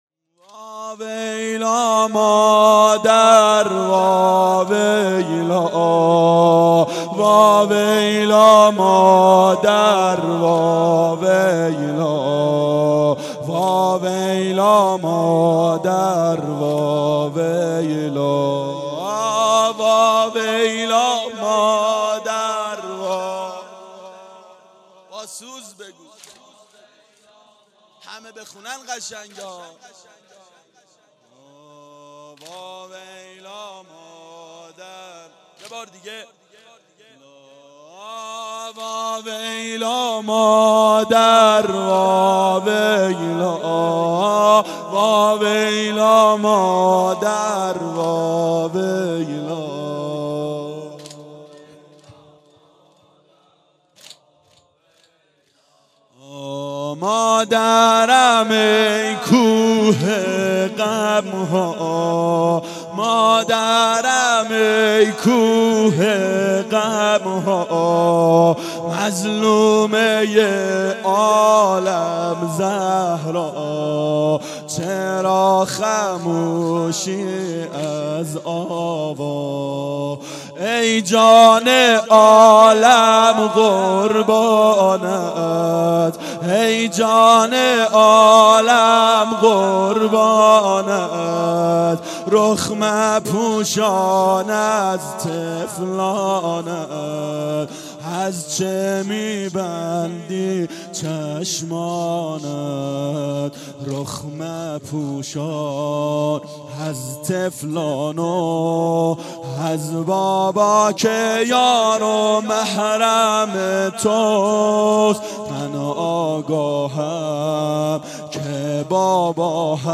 فاطمیه اول 92 هیأت عاشقان اباالفضل علیه السلام منارجنبان